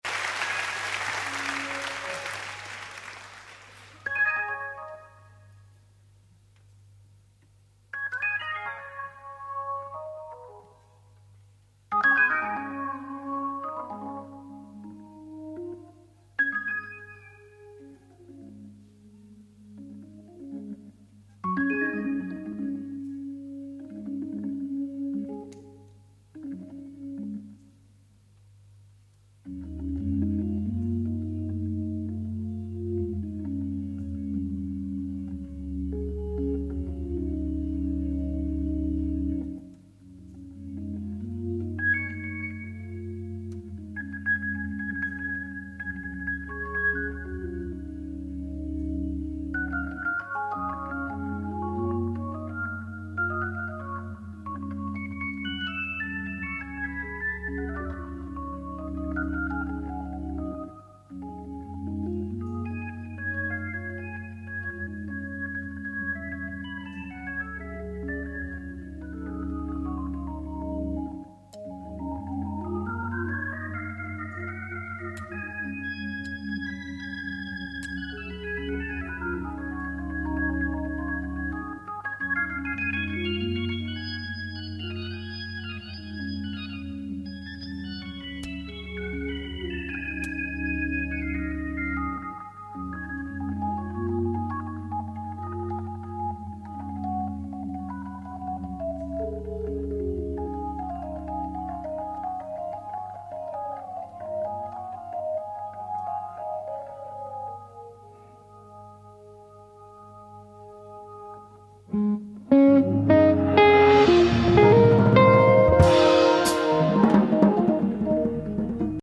enregistré en public
le deuxième avec les effets divers